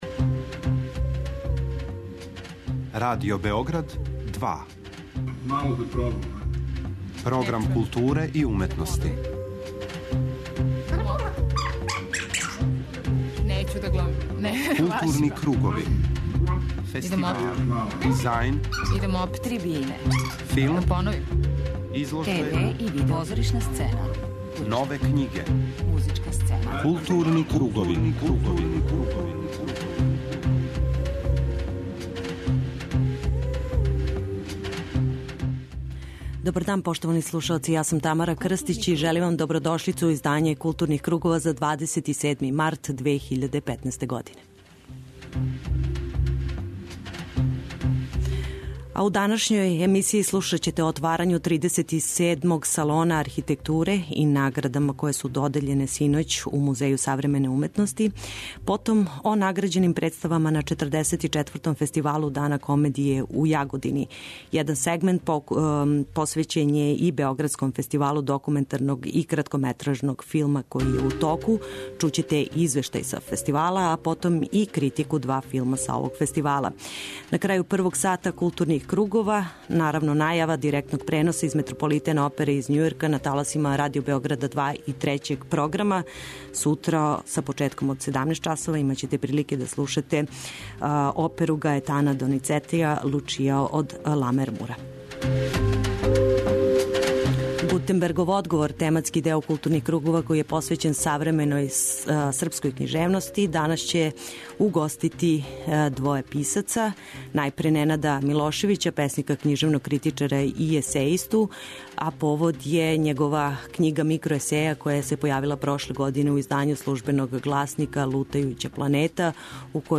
У другом делу емисије слушаћете разговор